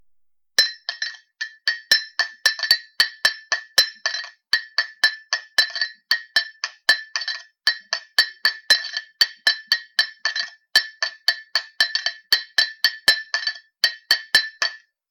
- Portal de Educación de la Junta de Castilla y León - Taza de crital y cuchara de metal
Sonido de una cuchara girando en e una taza.